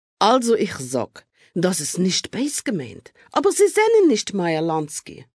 Roughly 50,000 words in various dialects of New York citizens were recorded just to capture the atmosphere in the city.
Fußgängerin: